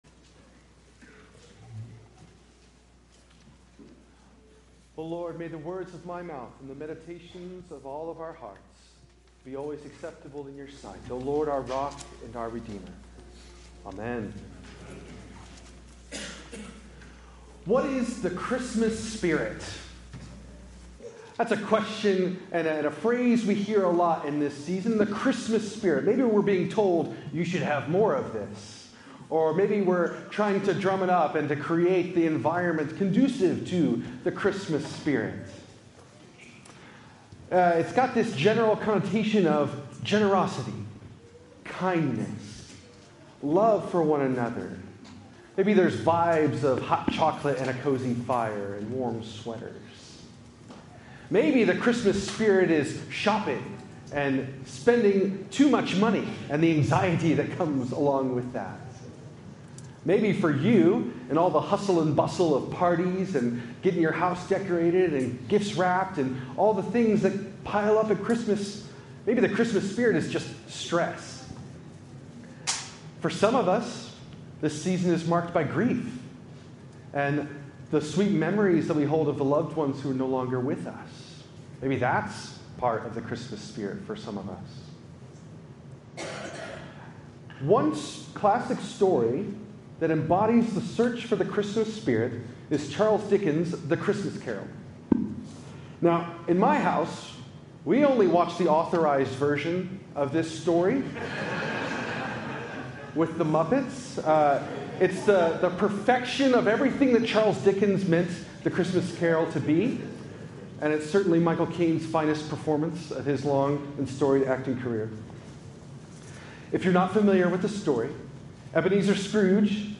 Luke 2:1-20 Tagged with Christmas , Faith , Good News , Incarnation , Worship